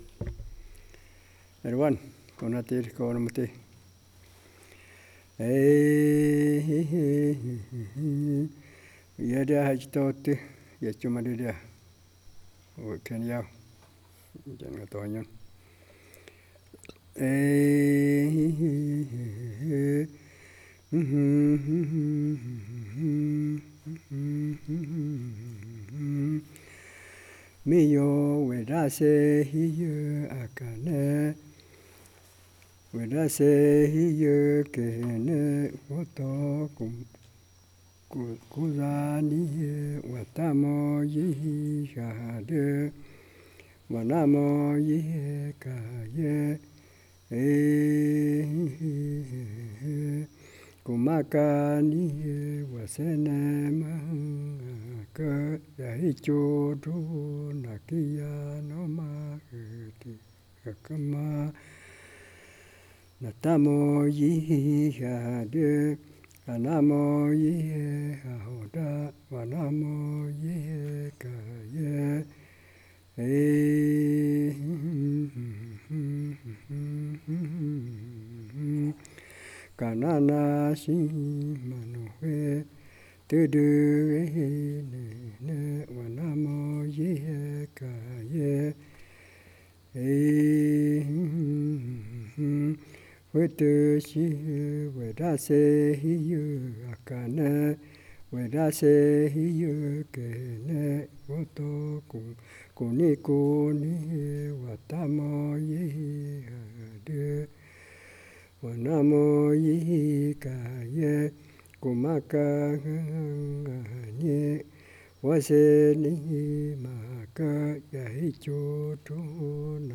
Execução do motivo melódico do canto Äji’choto otö yaichuumatoojo. acchudi ou yaichuumadö. Cantador